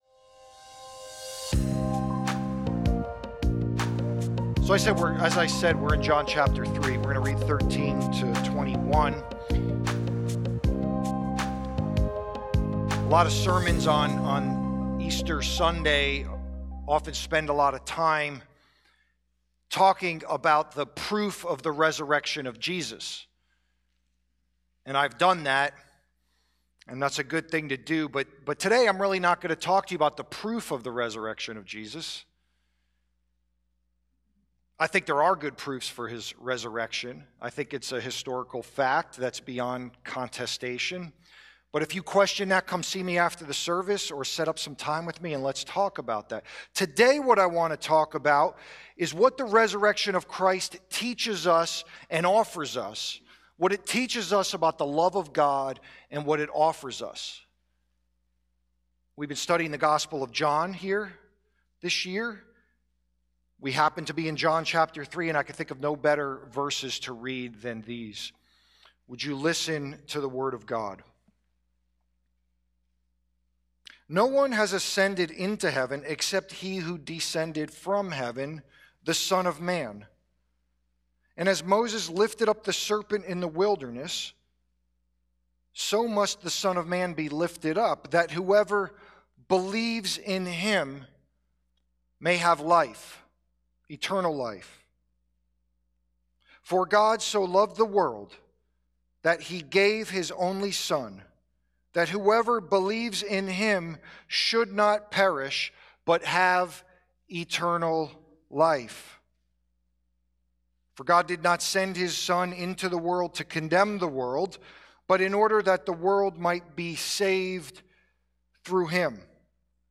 SERMON – 4 Ways the “world” Gets John 3:16 Wrong